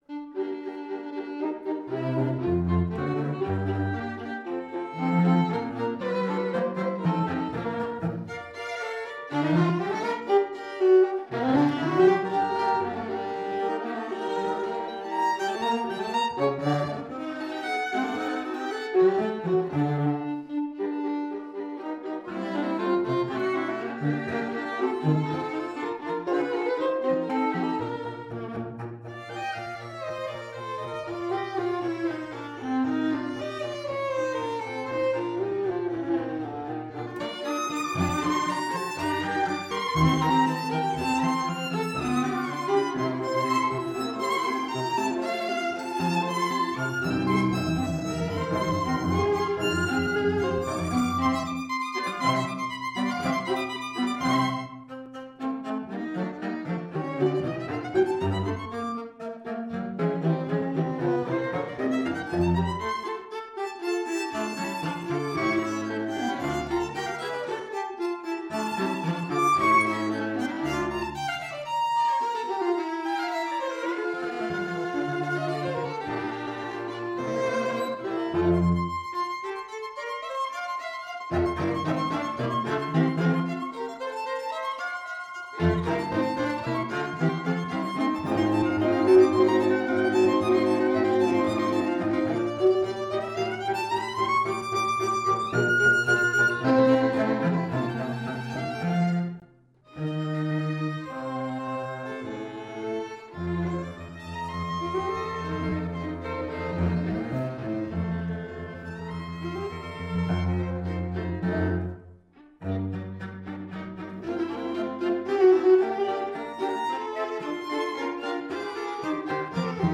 Soundbite 4th Movt
The Finale, though not given a tempo making is clearly an Allegro characterized by its chromaticism.
krommer-op19-no3-movt4.mp3